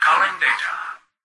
"Culling data" excerpt of the reversed speech found in the Halo 3 Terminals.